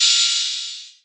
DDWV OPEN HAT 6.wav